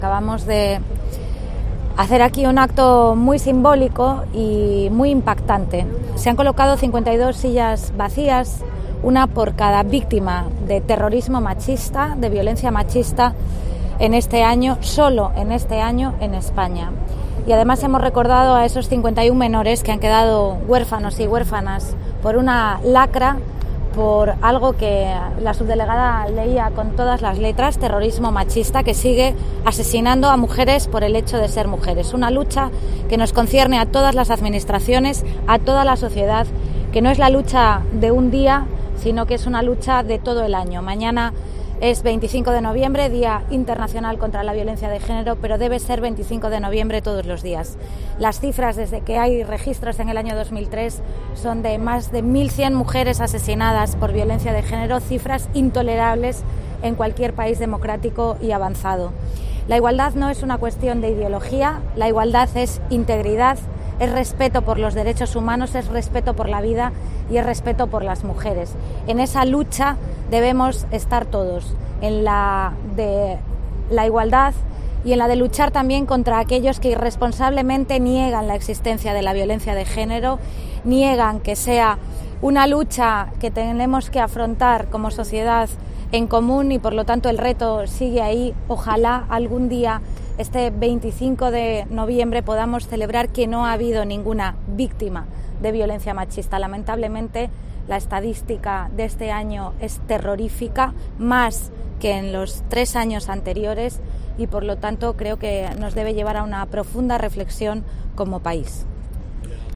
Inés Rey, alcaldesa de A Coruña